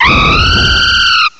cry_not_talonflame.aif